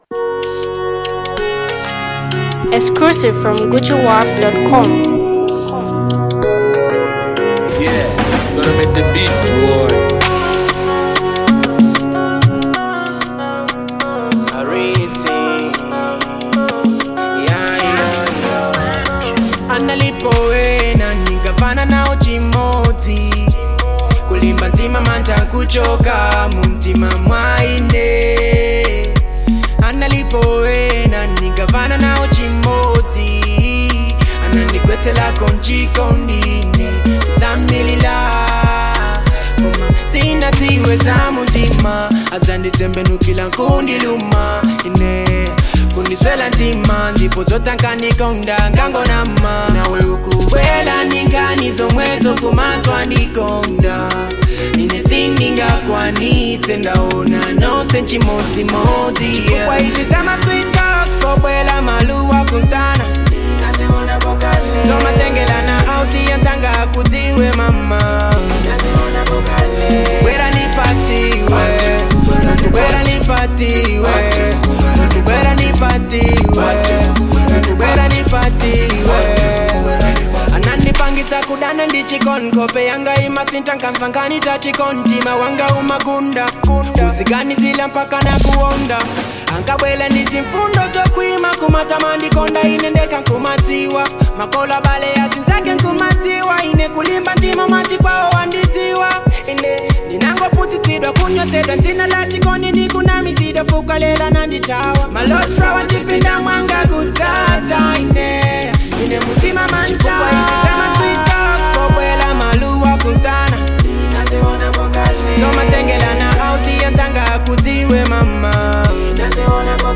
The Malawian up talented rap sensation artist
very powerful melodic hit jam